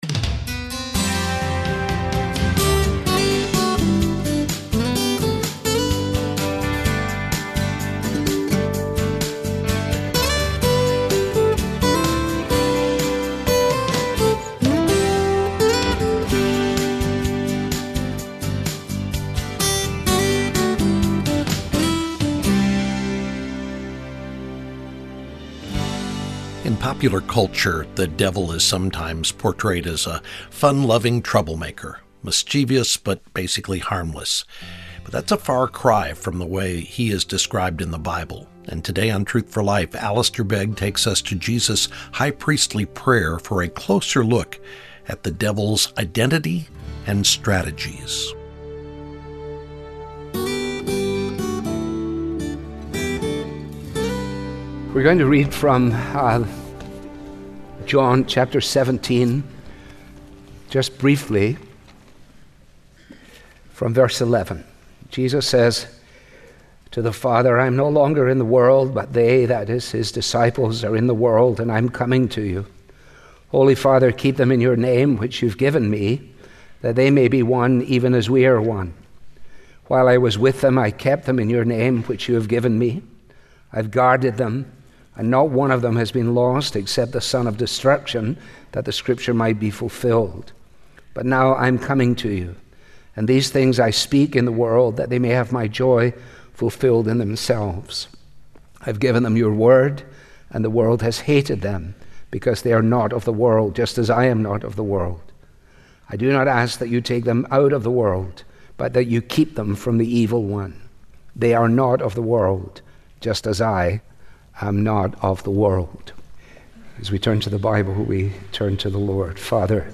Truth For Life is the Bible-teaching ministry of Alistair Begg.